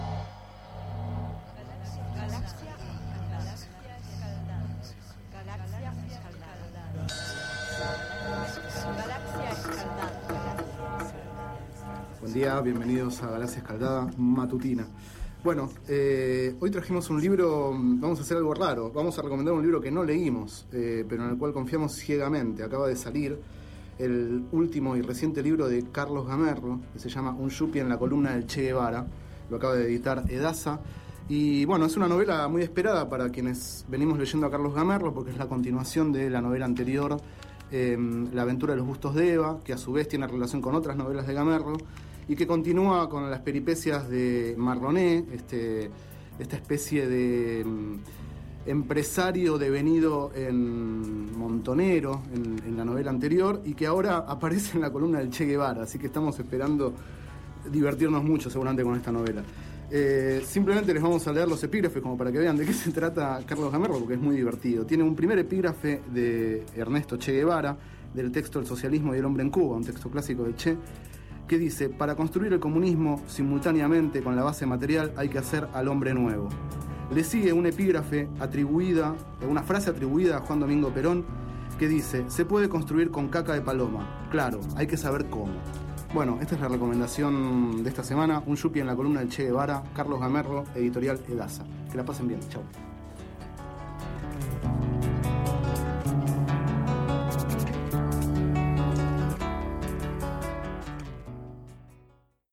Este es el 3º micro radial, emitido en el programa Enredados, de la Red de Cultura de Boedo, por FMBoedo, realizado el 19 de marzo de 2011, sobre el libro Un yuppie en la columna del Che Guevara, de Carlos Gamerro.